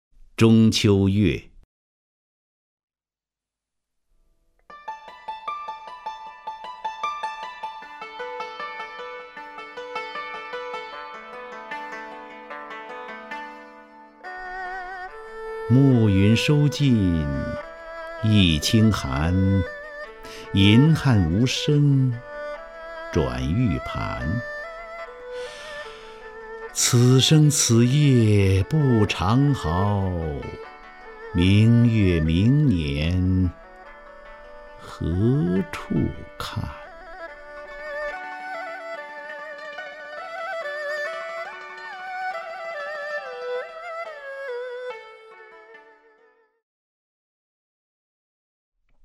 张家声朗诵：《中秋月》(（北宋）苏轼) （北宋）苏轼 名家朗诵欣赏张家声 语文PLUS